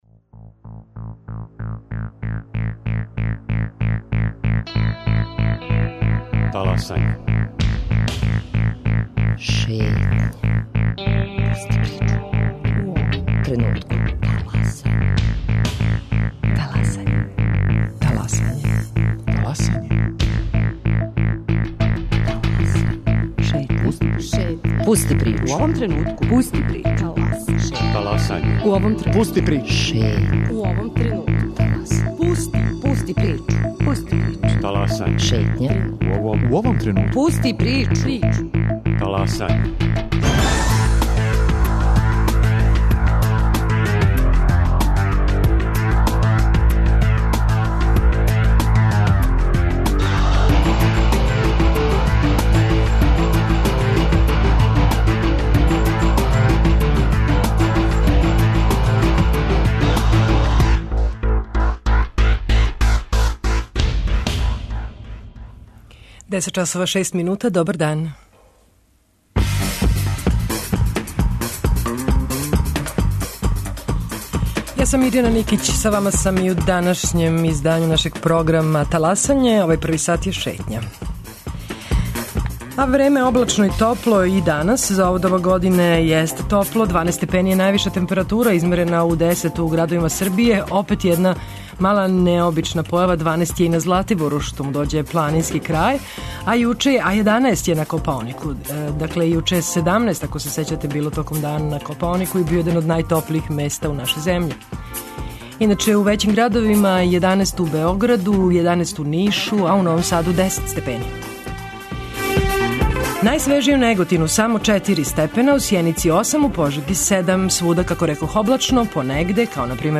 Шетњу бисмо могли насловити као “Три приче из Србије”: из Бечеја, Прибоја и Нове Вароши, а забележили су их репортери Радио Београда 1.